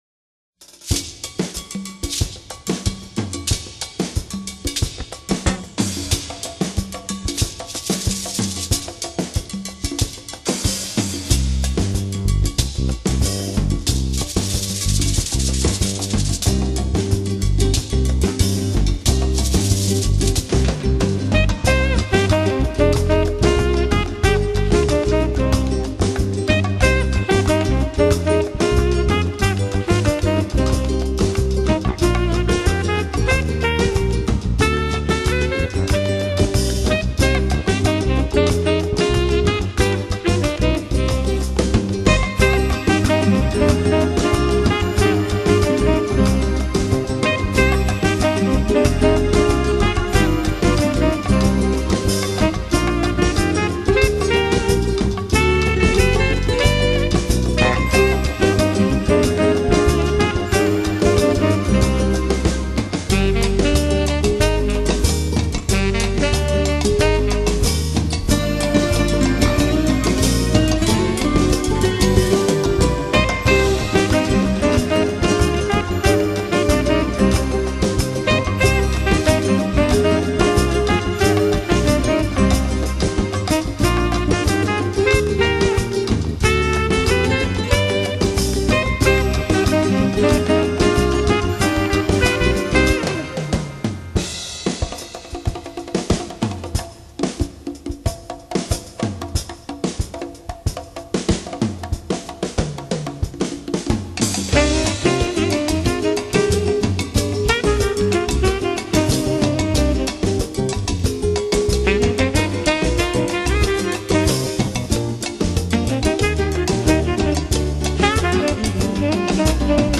萨克斯管
充满器乐细碎的敲击，空气也随之舞蹈和扭动